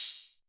Open Hat 1 [ basic ].wav